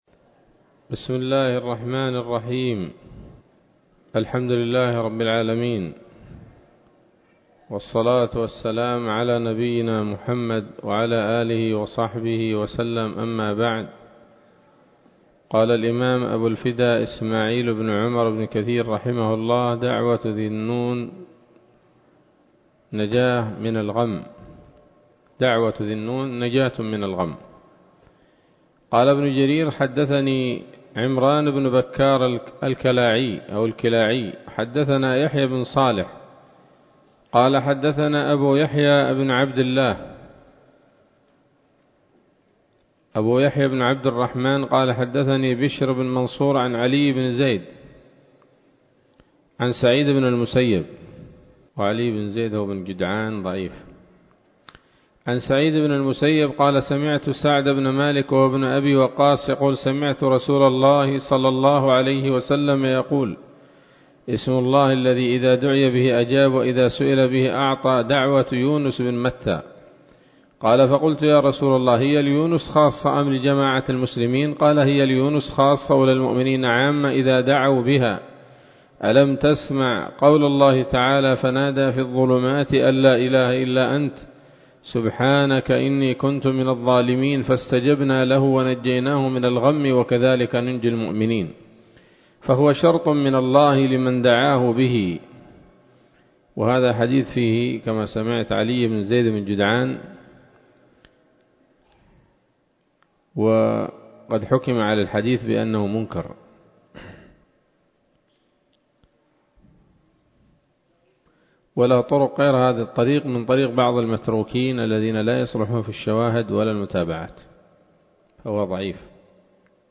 الدرس الثمانون من قصص الأنبياء لابن كثير رحمه الله تعالى